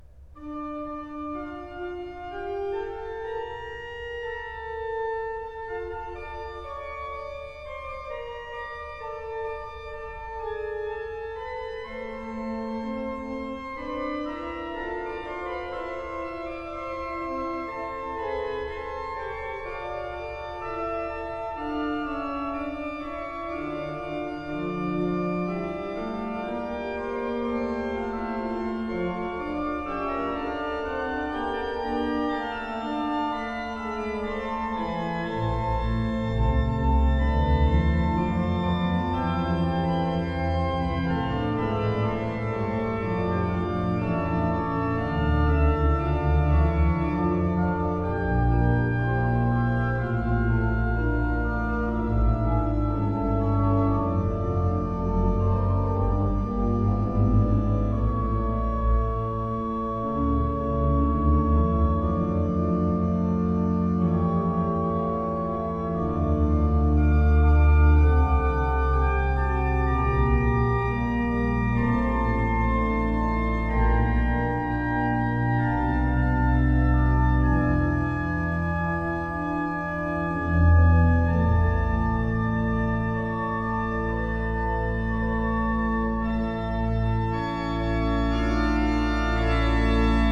Musica Sacra